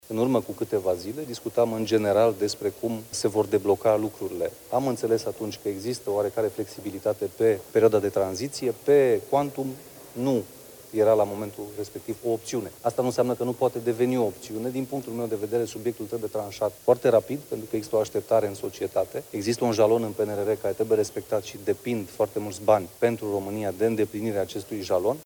Președintele Senatului, Mircea Abrudean: „Există un jalon în PNRR care trebuie respectat și depind foarte mulți bani pentru România de îndeplinirea acestui jalon”